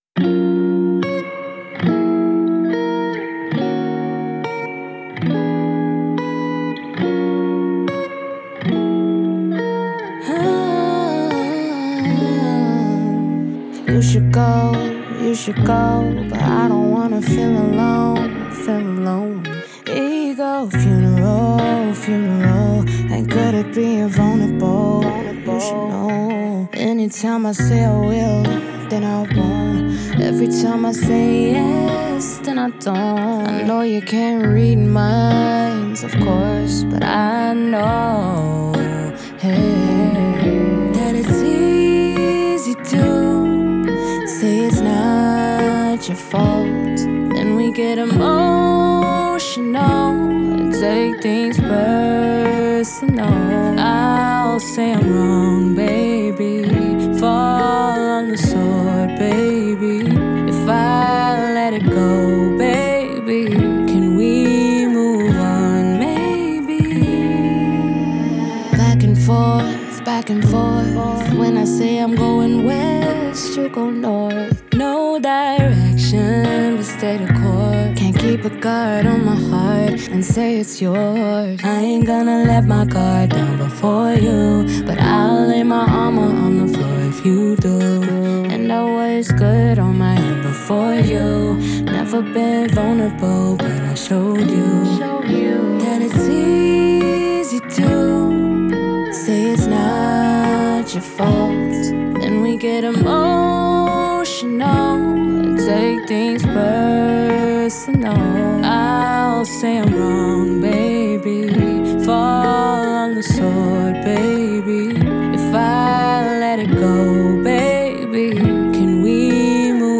R&B
Eb Min